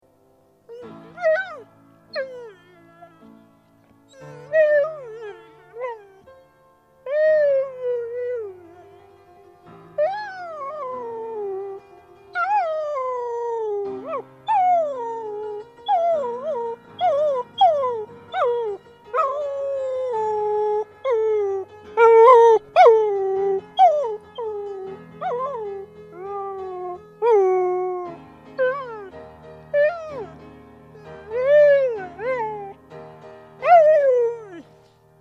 Jezebel, The Singing Dog